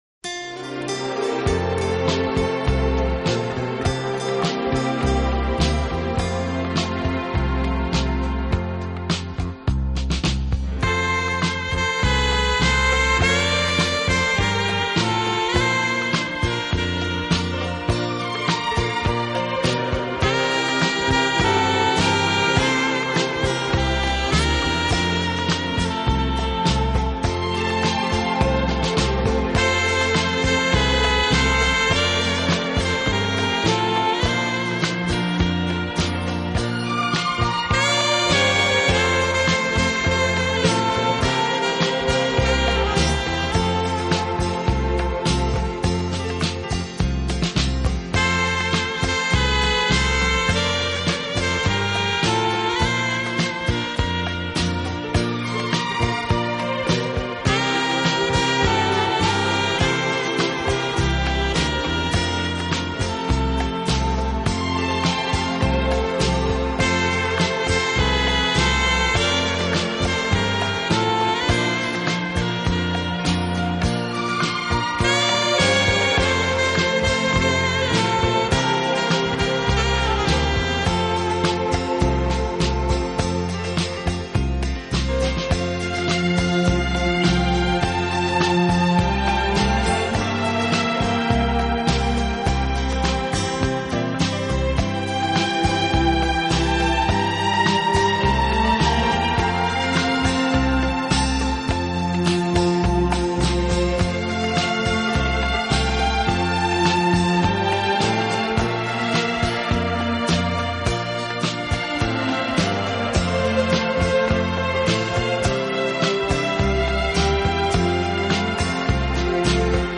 【顶级轻音乐】
引力和动人心弦的感染力。